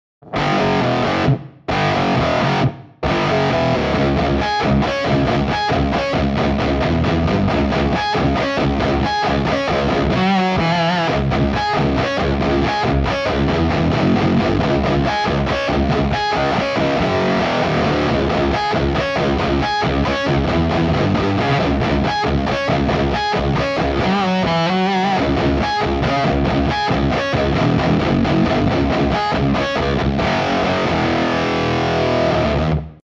Harmonics of the Stone (just octave)
Harmonics-of-the-Stone.wav-just-OCT.mp3